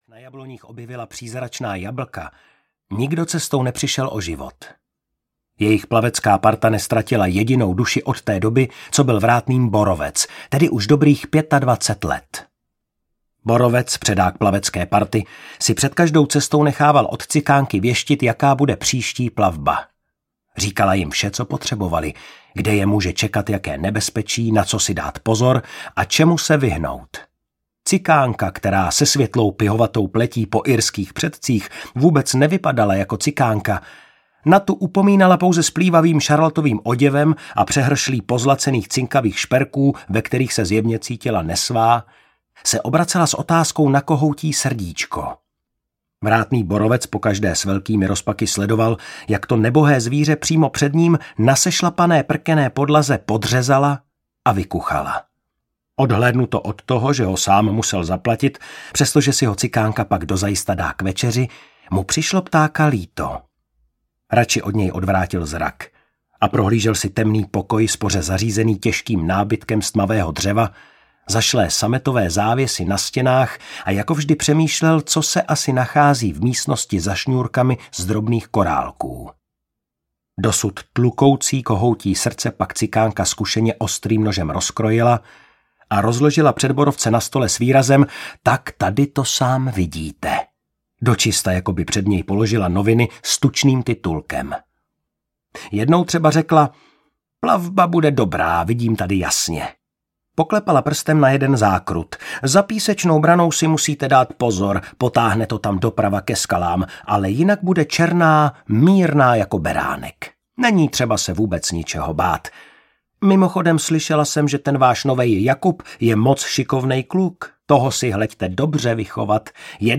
Ukázka z knihy
druha-strana-reky-audiokniha